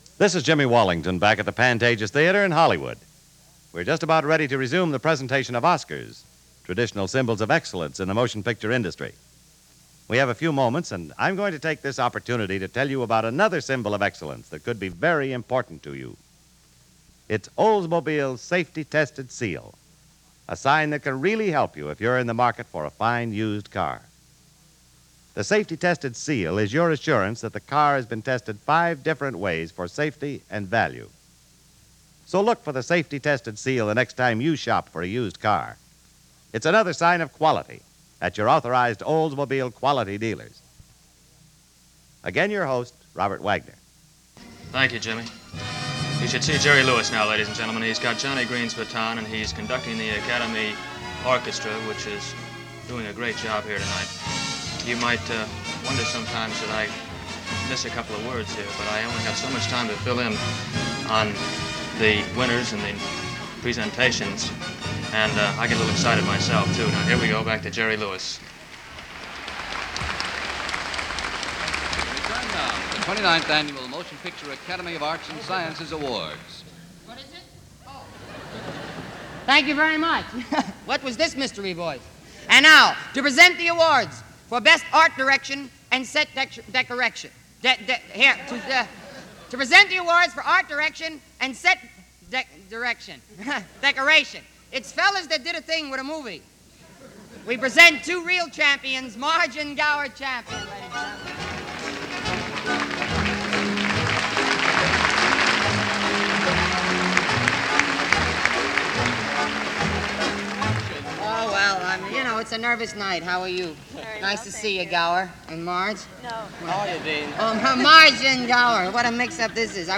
But this was the radio version – yes, radio was still important, and not wanting to take backseat to television in something as culturally important as this, there had to be a radio version. This one was narrated by veteran actor Robert Wagner, and since it was live, everything was off the cuff and there was nothing slick or rehearsed about it.